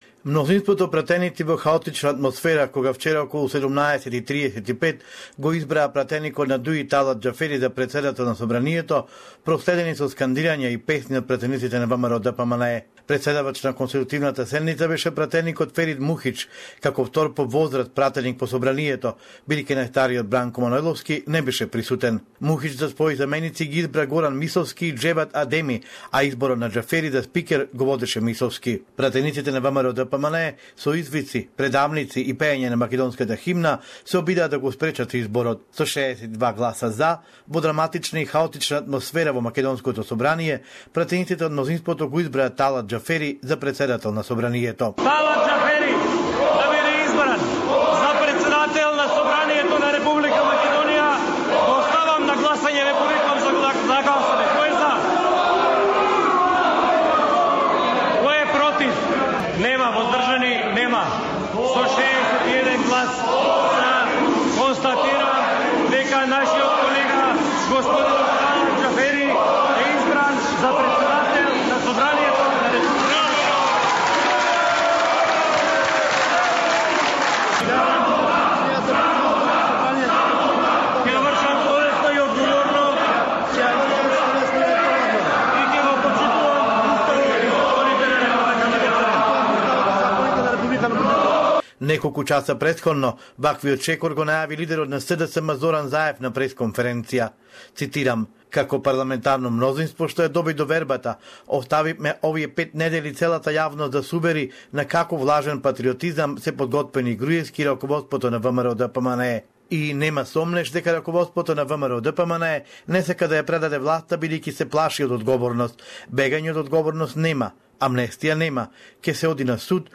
SBS Macedonian report